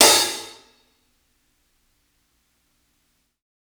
60s_OPEN HH_2.wav